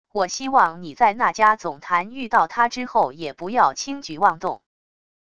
我希望你在那伽总坛遇到她之后也不要轻举妄动wav音频生成系统WAV Audio Player